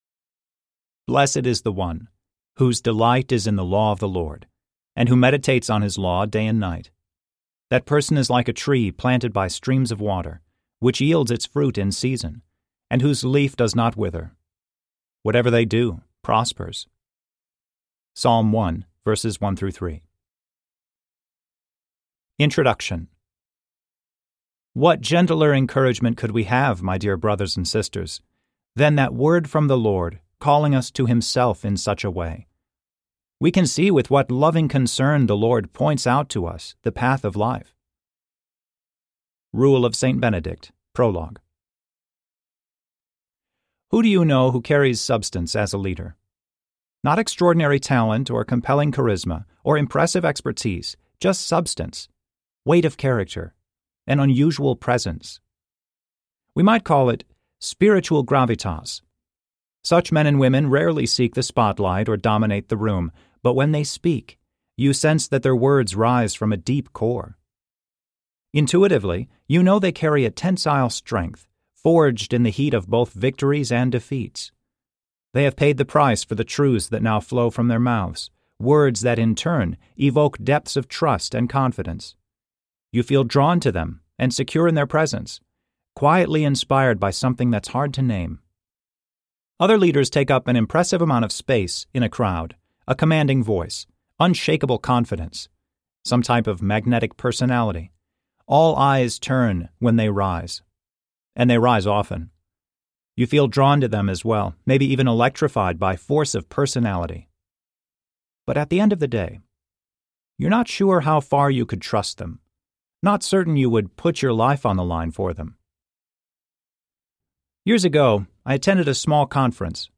Gravitas Audiobook
Narrator
5.7 Hrs. – Unabridged